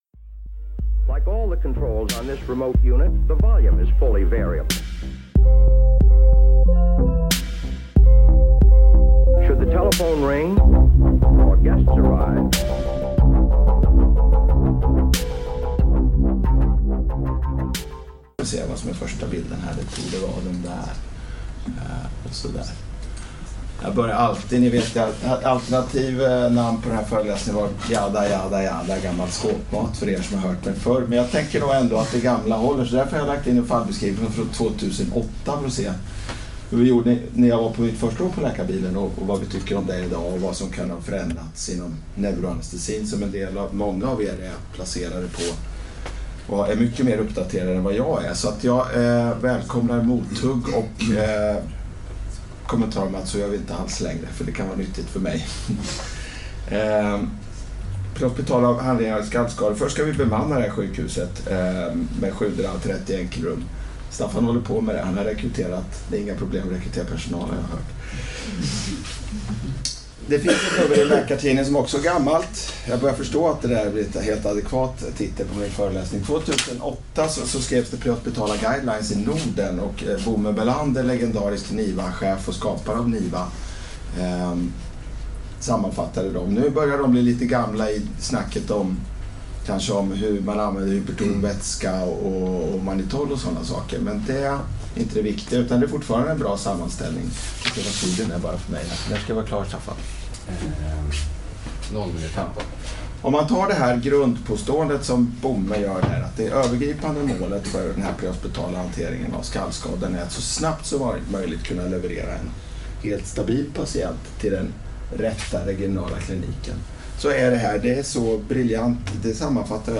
när han föreläser på ST-fredagen om prehospital sjukvård och katastrofmedicin den 1 december 2017 i Solna.